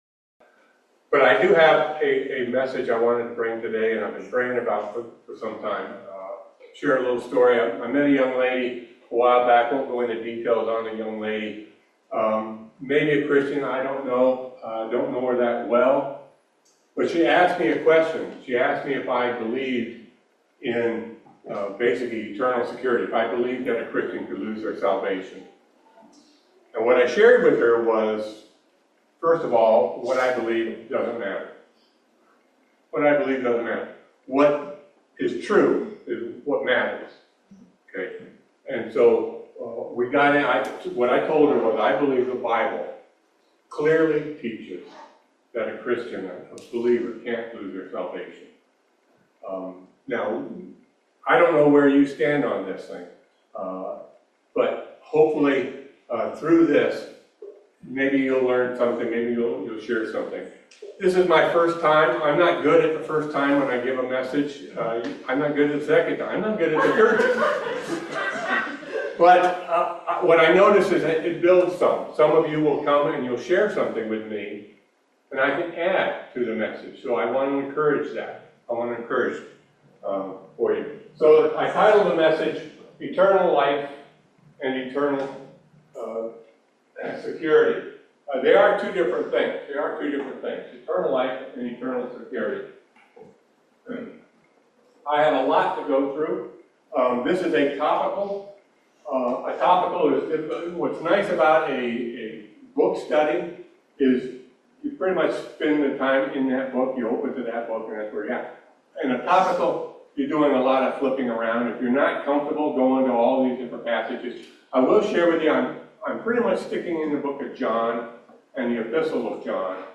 67 Service Type: Family Bible Hour Can a Christian lose their salvation?